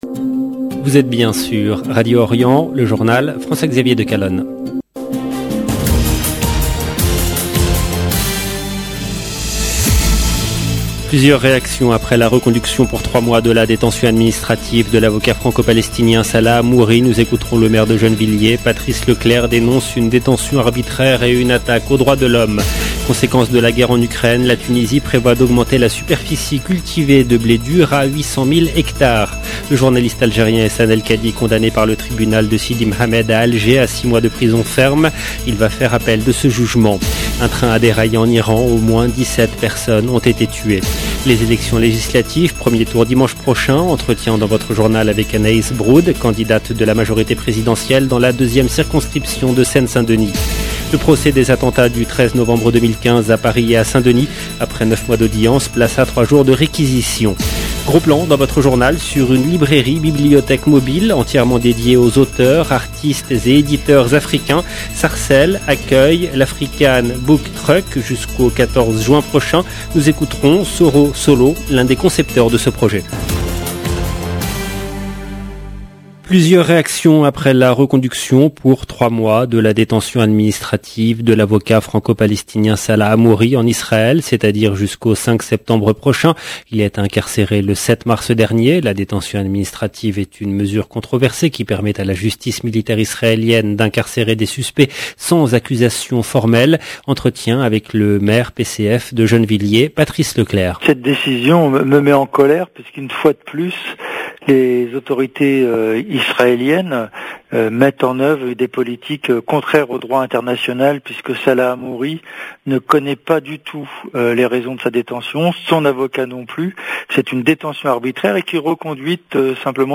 Journal présenté par
Nous écouterons le maire de Gennevilliers Patrice Leclerc.